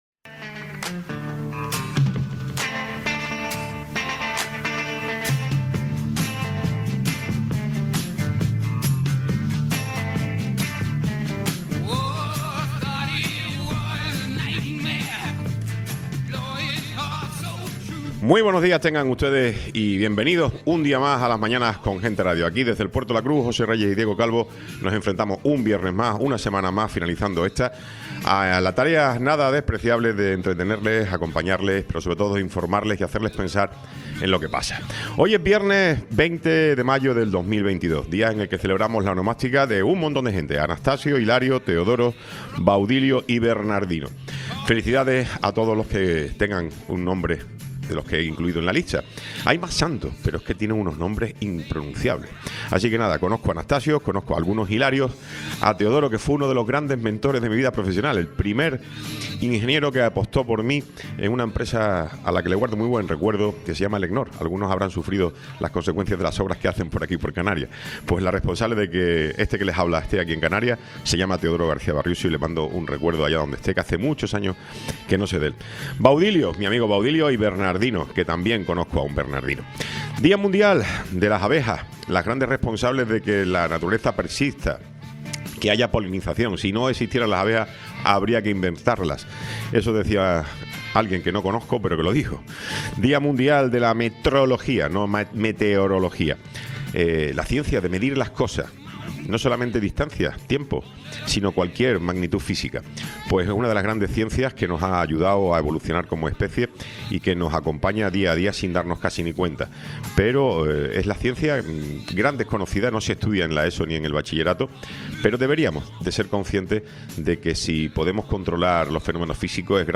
Tiempo de entrevista
Tertulia